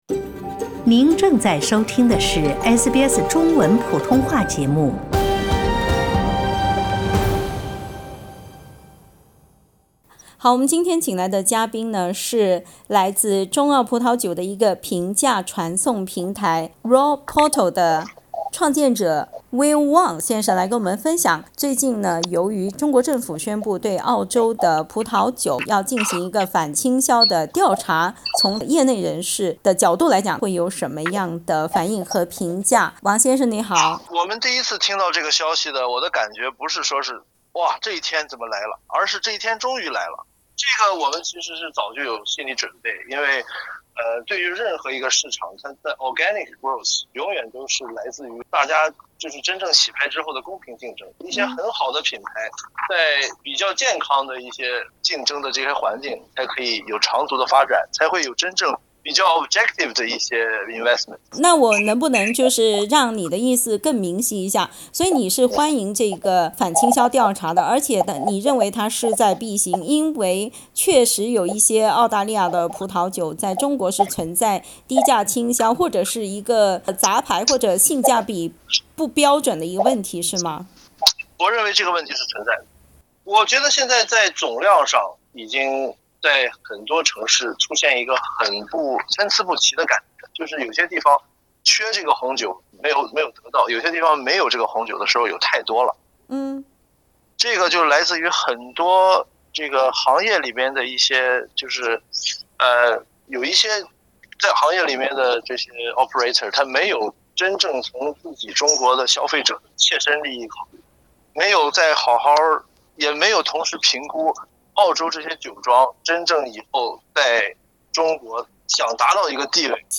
请听采访。 （本节目为嘉宾观点，不代表本台立场。）